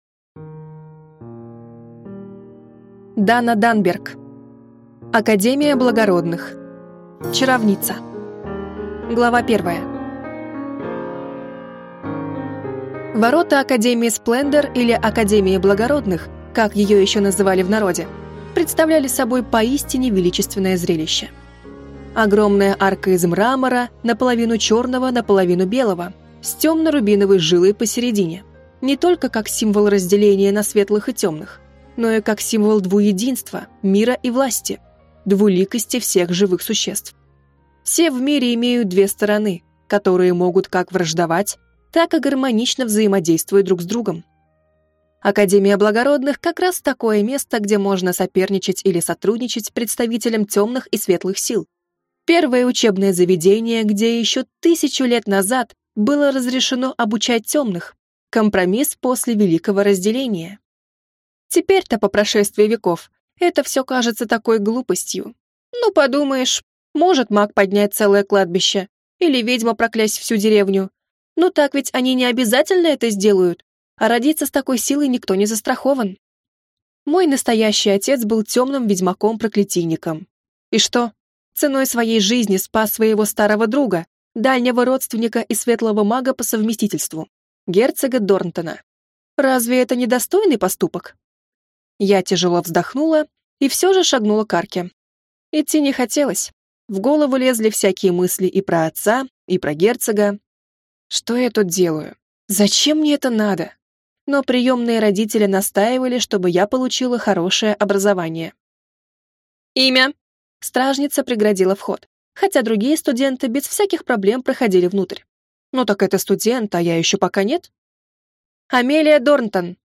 Аудиокнига Академия благородных. Чаровница | Библиотека аудиокниг